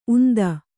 ♪ unda